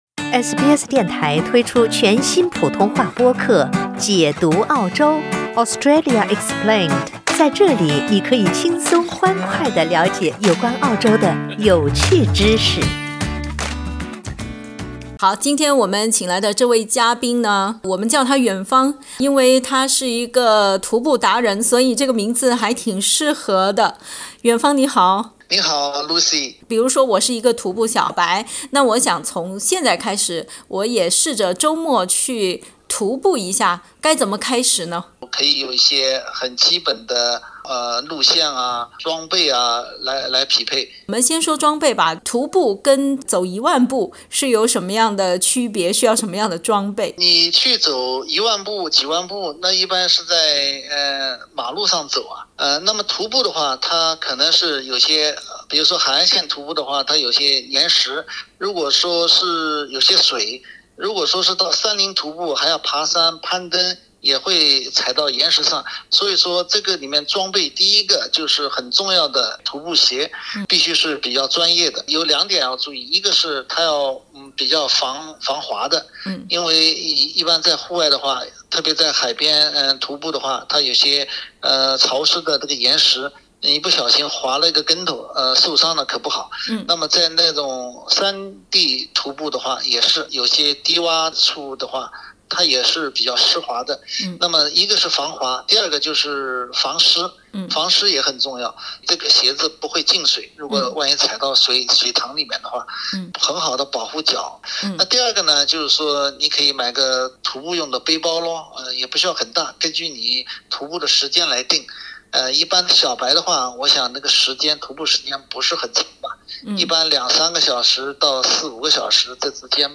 徒步也被称为远足（Hiking），它不是通常意义上的散步。（点击图片收听采访）